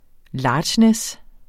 Udtale [ ˈlɑːdɕnεs ]